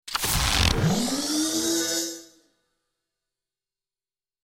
دانلود صدای ربات 25 از ساعد نیوز با لینک مستقیم و کیفیت بالا
جلوه های صوتی